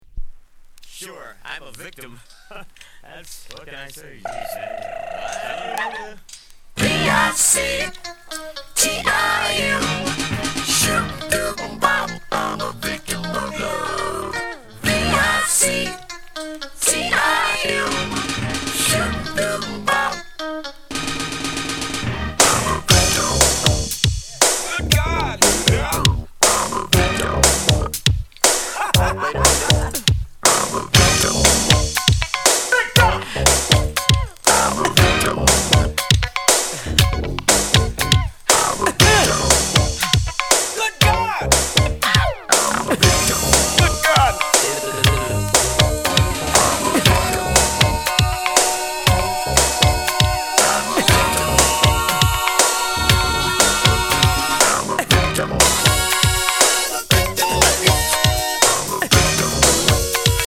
Genre: Funk